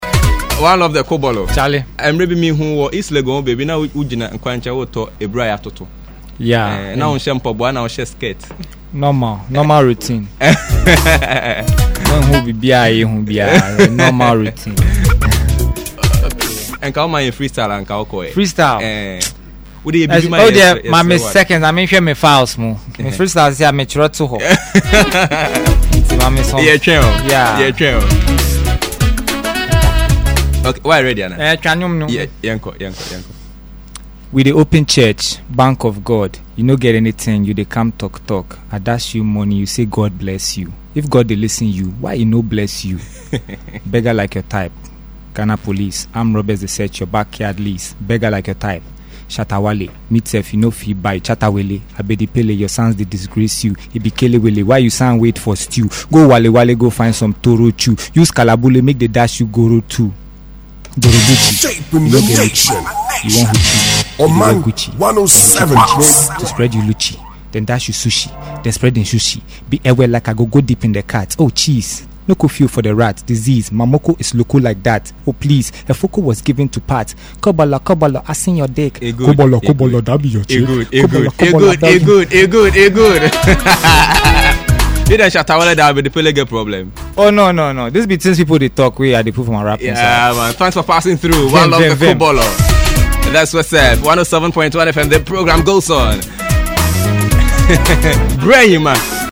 He entered the studios of OMAN FM unannounced around 11:30 in the morning yesterday in his usual Electricity Company helmet and barefooted.
give freestyle before saying goodbye